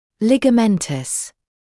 [ˌlɪgə’mentəs][ˌлигэ’мэнтэс]связочный